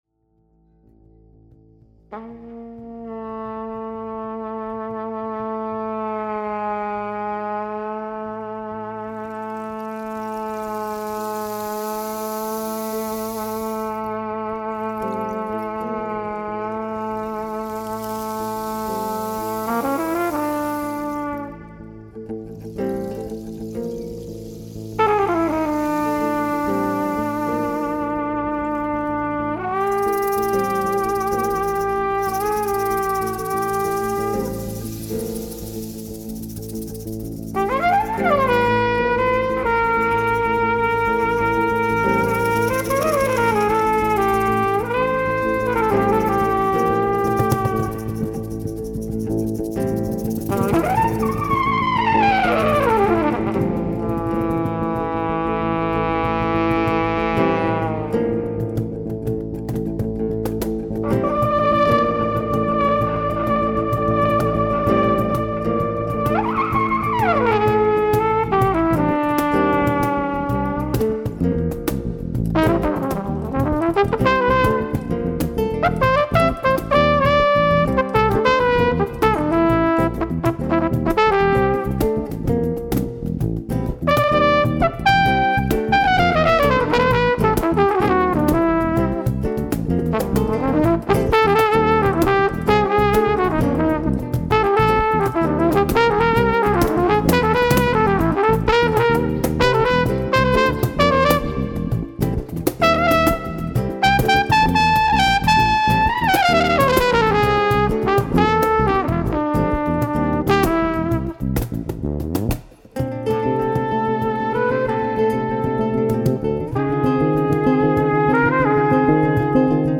Brass